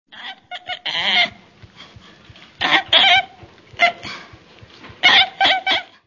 orangutan-pongo-pygmaeus.mp3